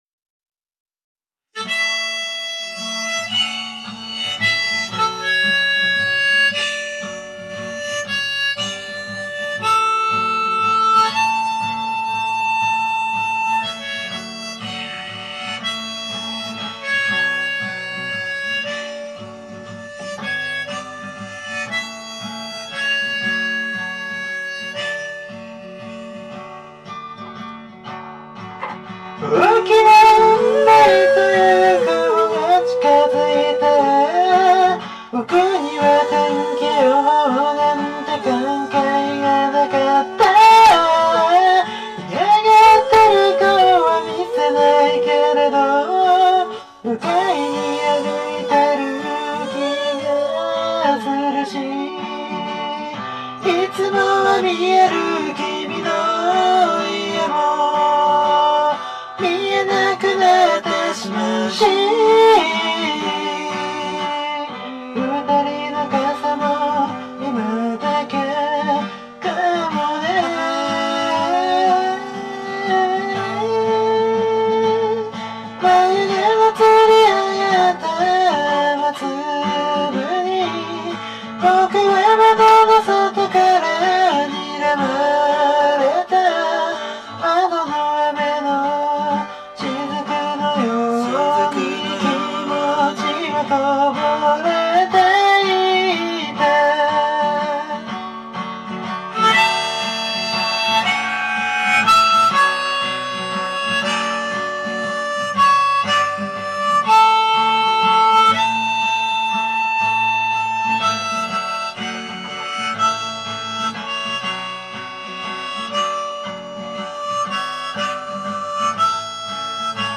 初にして三拍子でのチャレンジで、ハモりもデモとして安定せず、ボロボロな仕上がりではあります。